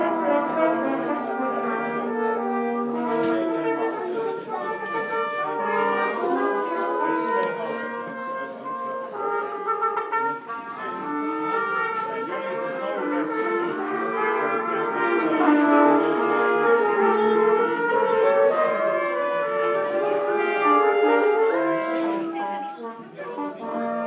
Warm up for class trumpet concert